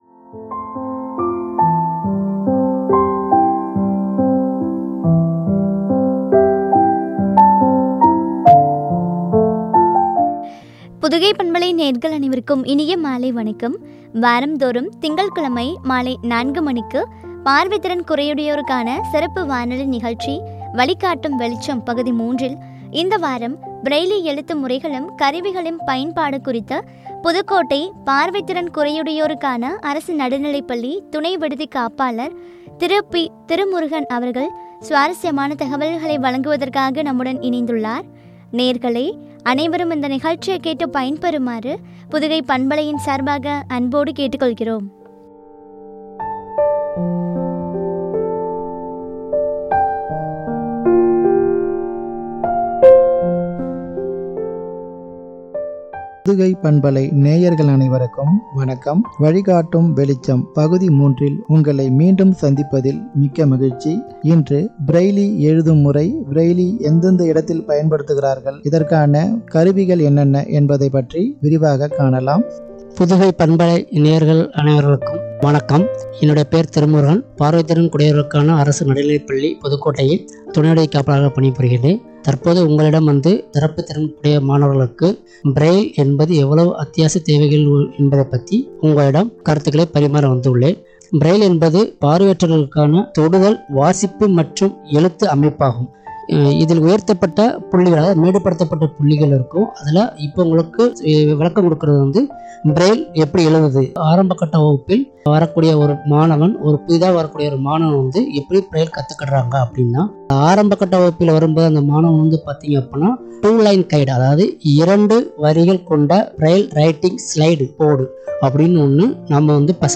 கருவிகளின் பயன்பாடும்” குறித்து வழங்கிய உரையாடல்.